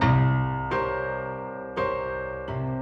Index of /musicradar/gangster-sting-samples/85bpm Loops
GS_Piano_85-C2.wav